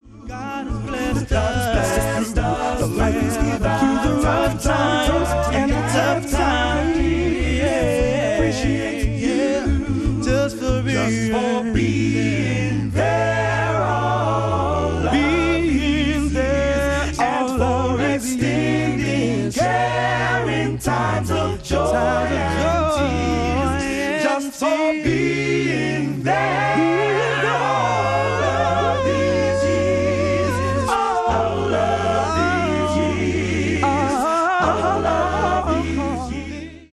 Your source for the best in A'cappella Christian Vusic ®